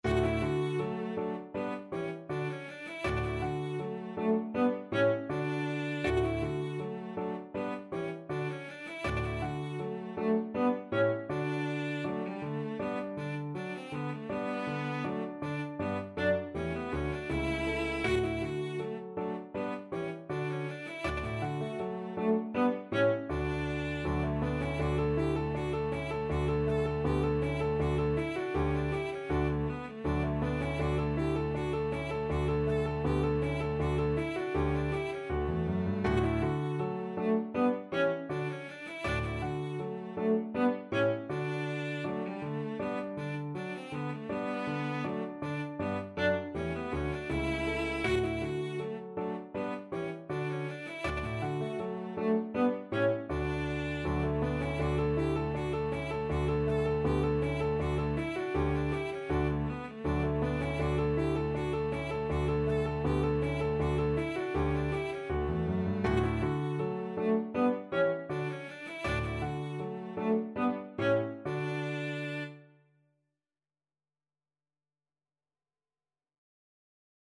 Cello
Scherzando =160
G4-A5
D major (Sounding Pitch) (View more D major Music for Cello )
Classical (View more Classical Cello Music)